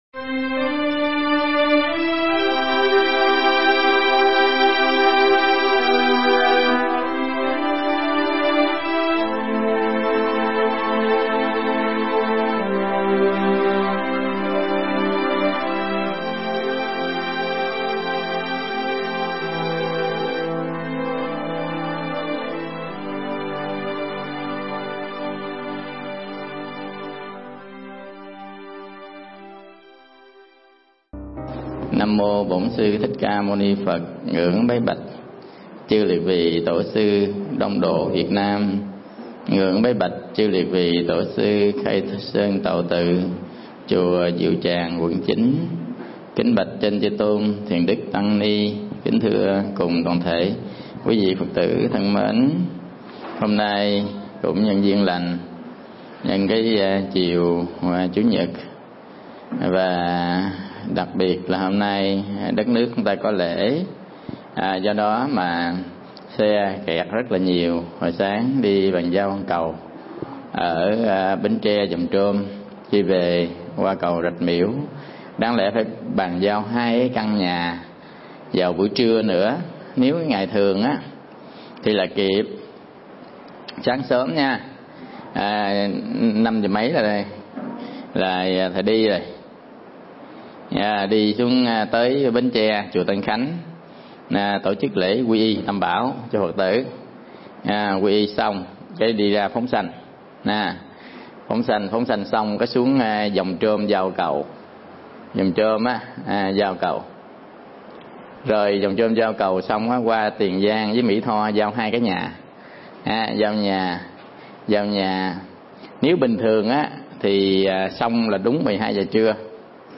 Mp3 Pháp Thoại Phật Học Danh Số